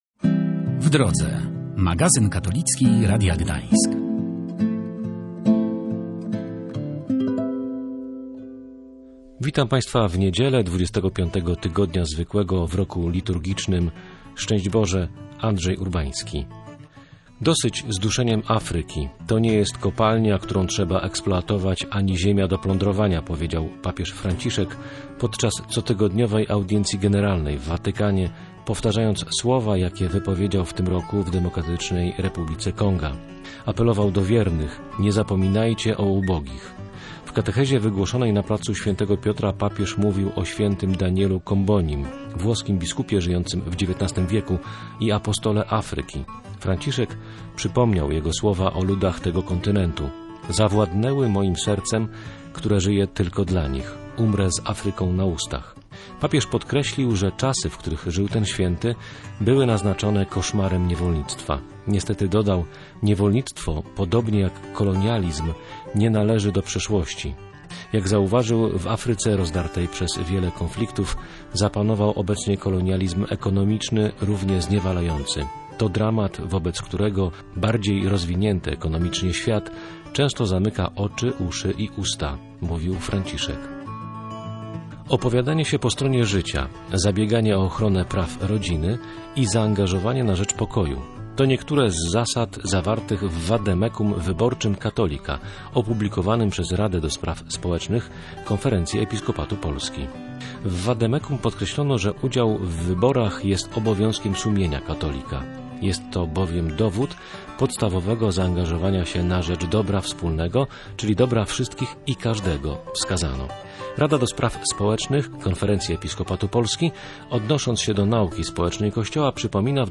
archiwum audycji katolicyzm religia W drodze magazyn katolicki